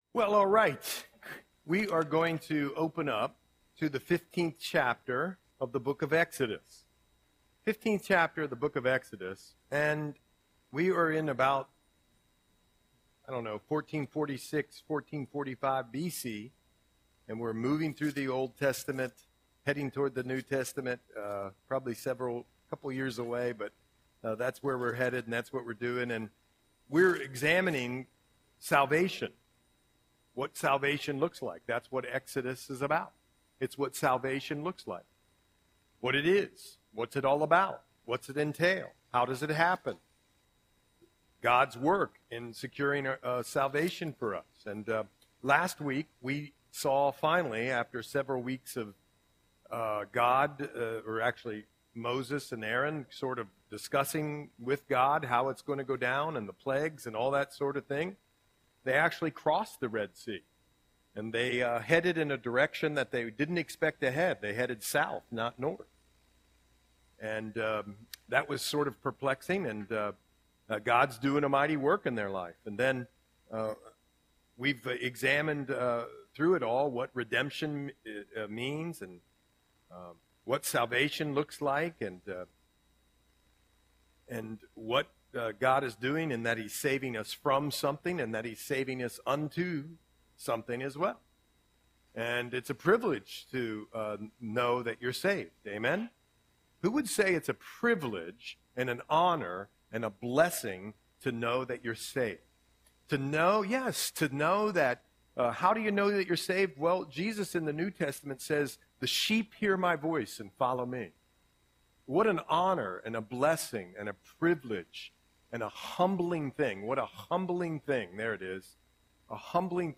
Audio Sermon - January 22, 2025